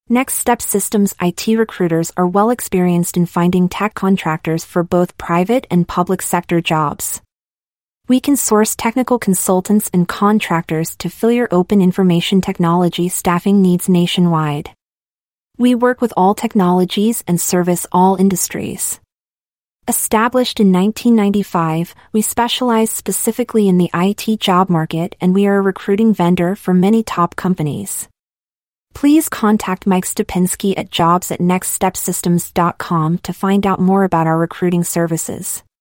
Hiring IT Contractors? Listen to Our Artificial Intelligence (AI) on How Our Specialized IT Recruiters Can Help You with Your IT Contract Staffing Requirements.
Please take a moment to listen to an audio file about our IT staffing company employer services generated by Artificial Intelligence (AI).